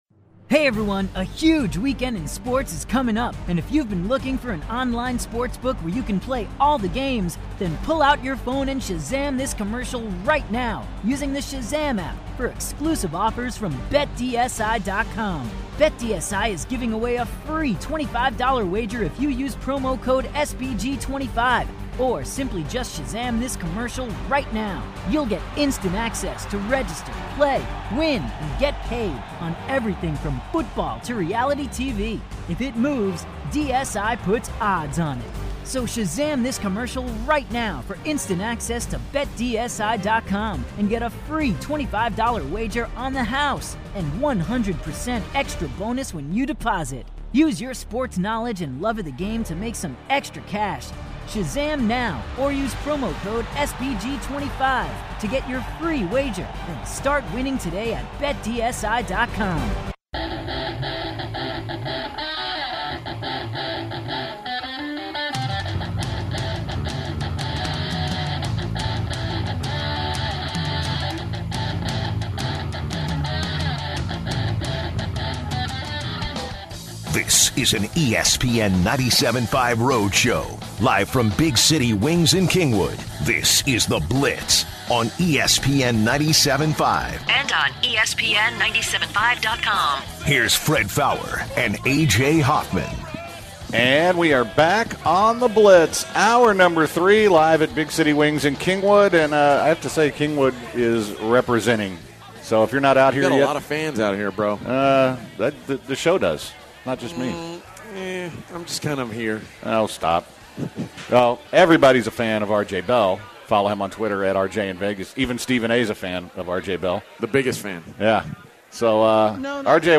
everything wraps up with “stupid stuff” and laugh tracks.